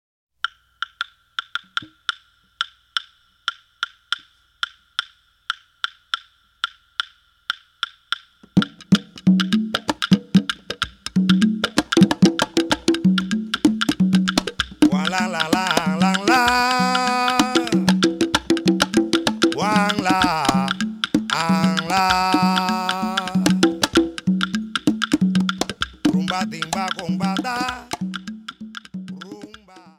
Темп: 122 bpm